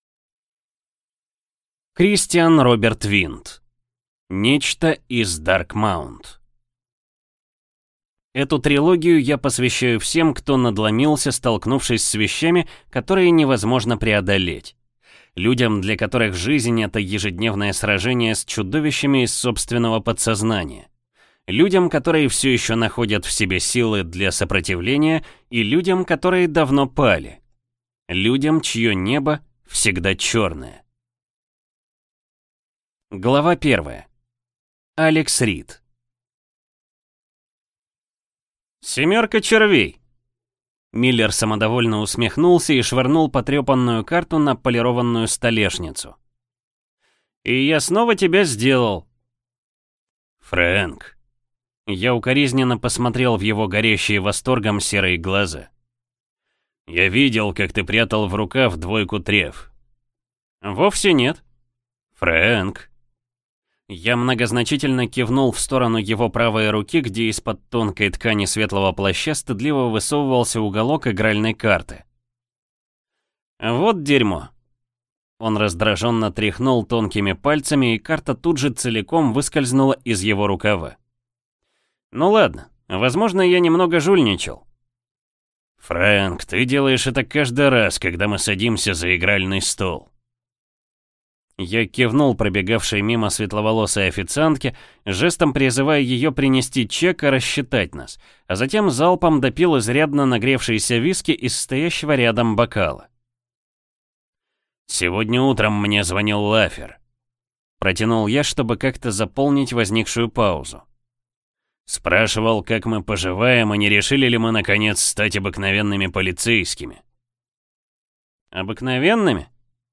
Аудиокнига Нечто из Дарк Маунт | Библиотека аудиокниг